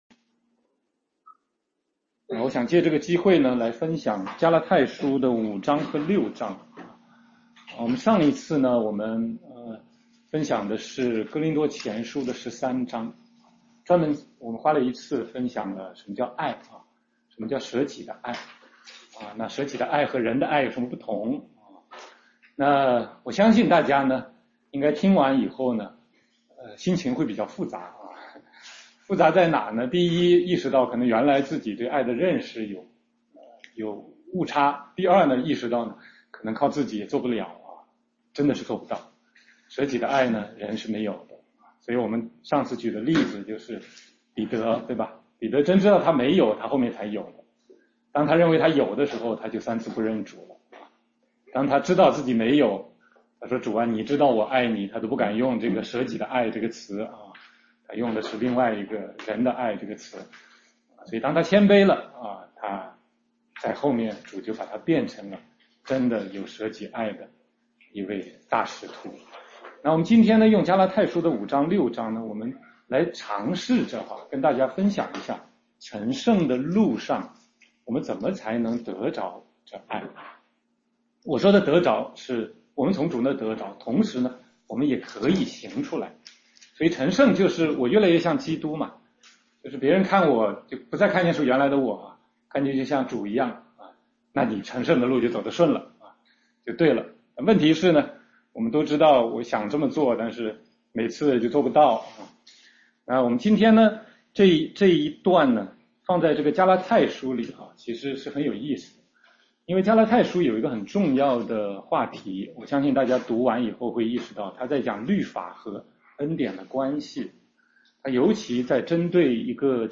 16街讲道录音 - 加拉太书5-6章—生发仁爱的信心
全中文查经